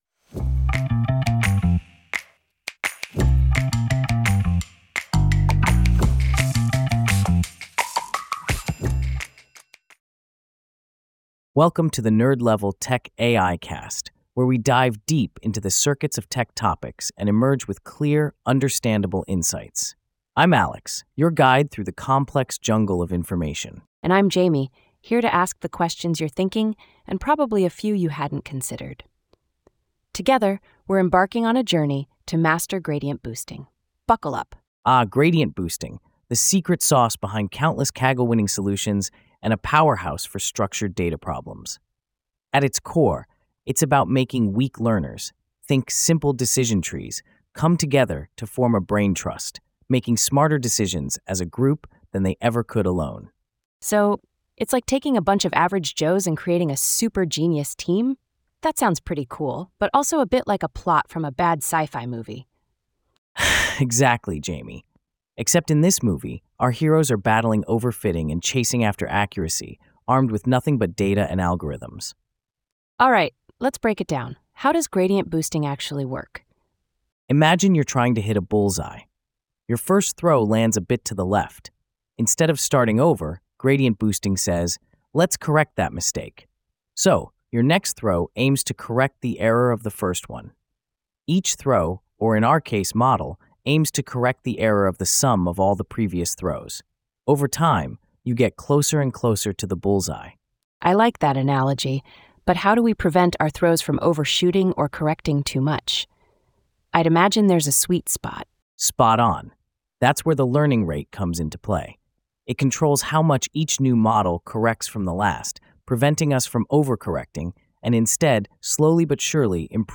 AI-generated